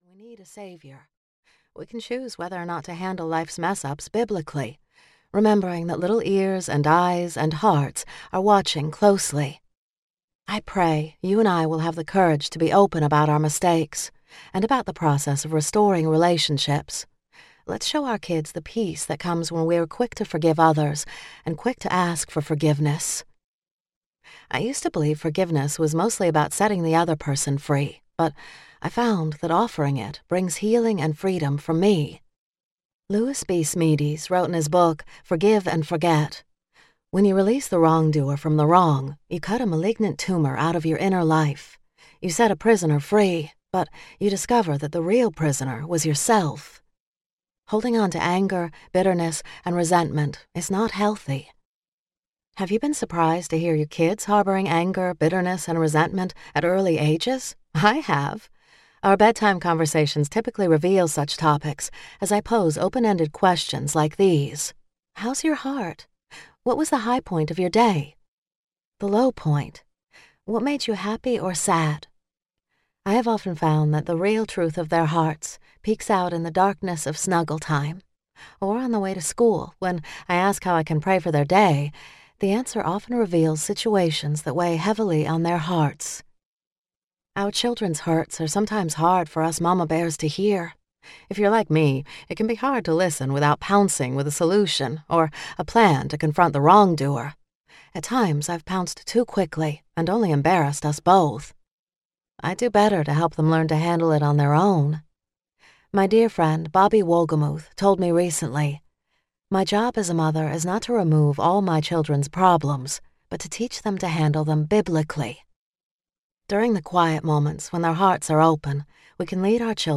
In This House, We Will Giggle: Making Virtues, Love, & Laughter a Daily Part of Your Family Life Audiobook